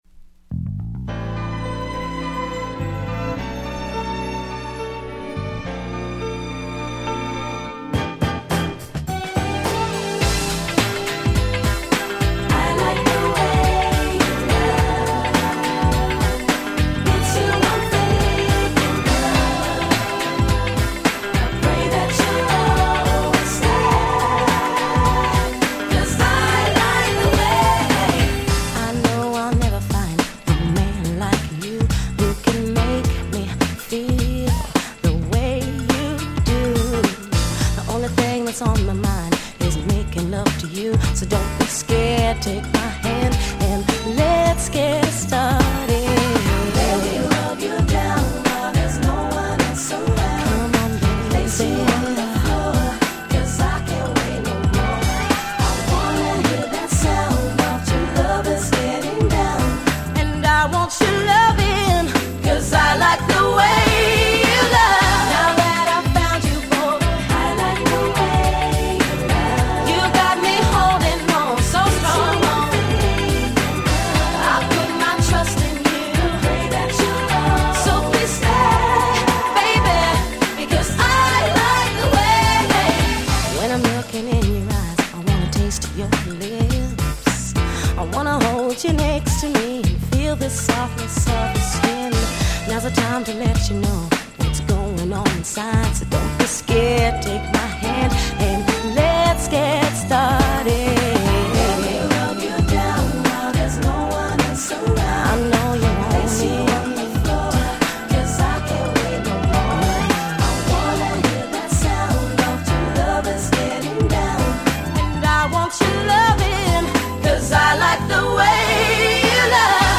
96' Nice R&B LP !!